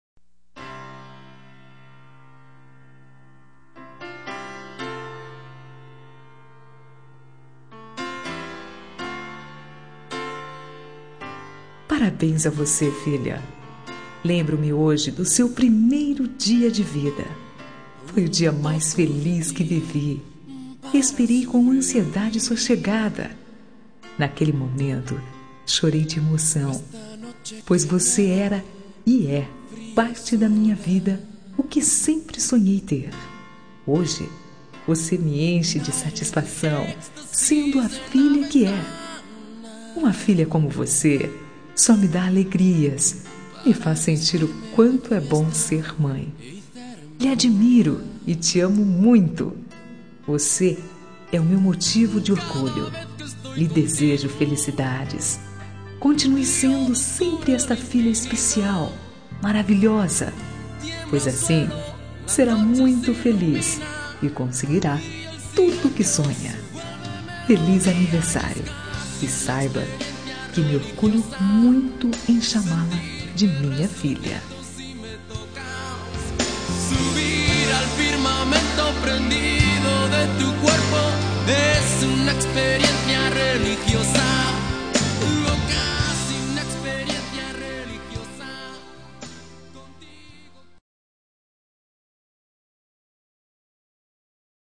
Telemensagem de Aniversário de Filha – Voz Feminina – Cód: 1762 – Maravilhosa